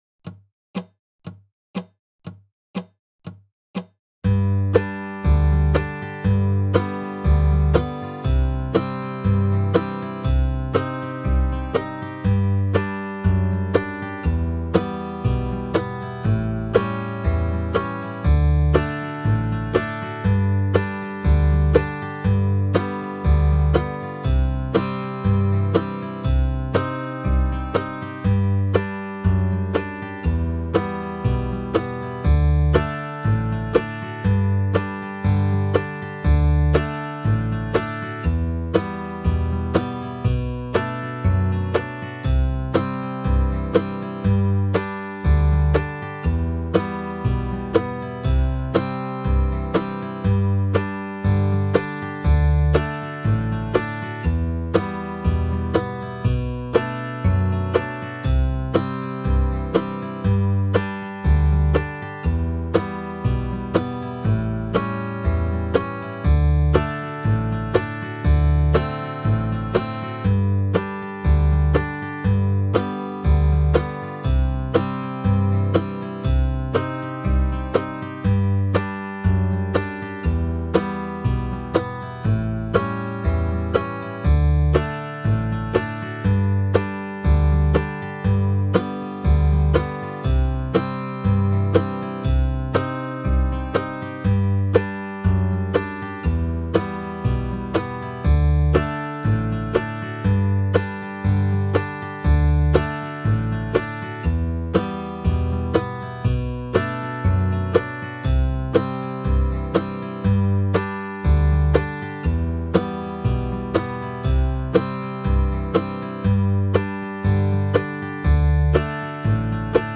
February 2025 Dobro Zoom Workshops
SM - Desperado - 60 BPM.mp3